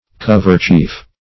Search Result for " coverchief" : The Collaborative International Dictionary of English v.0.48: Coverchief \Cov"er*chief\ (ch[=e]f), n. [See Kerchief .]